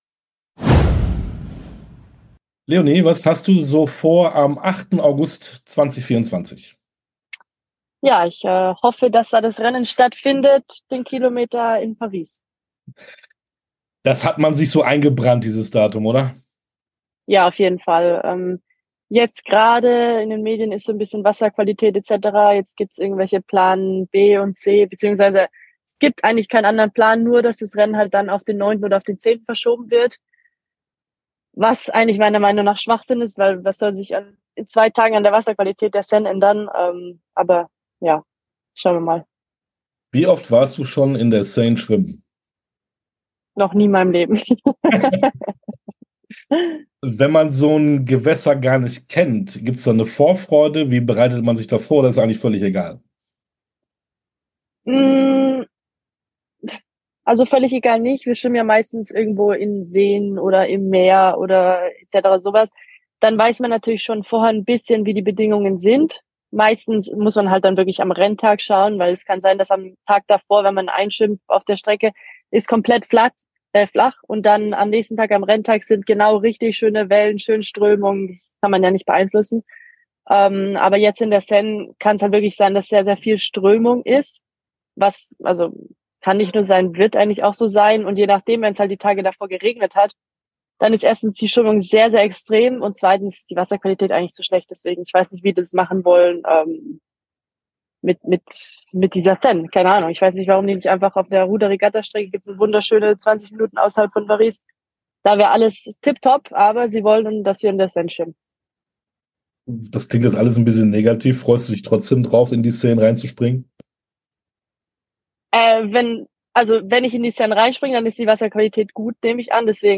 Sportstunde - Interview komplett Leonie Beck, Freiwasserschwimmerin ~ Sportstunde - Interviews in voller Länge Podcast